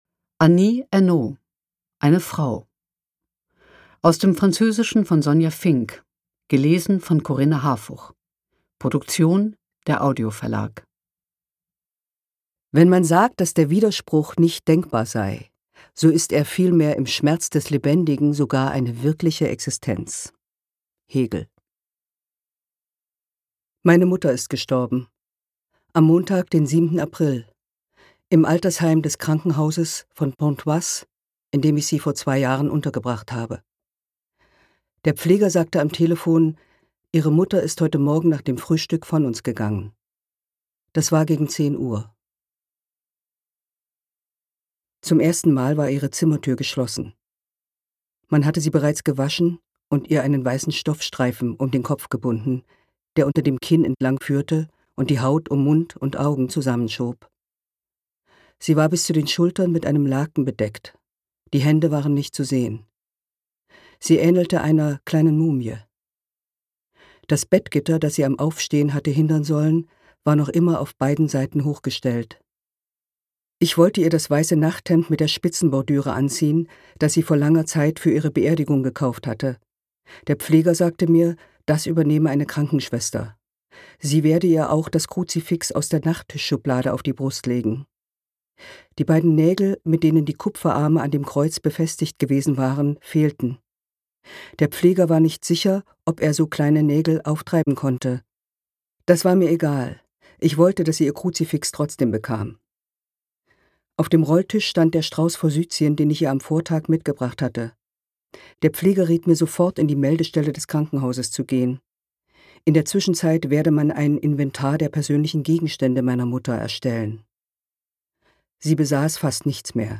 Ungekürzte Lesung mit Corinna Harfouch (2 CDs)
Corinna Harfouch (Sprecher)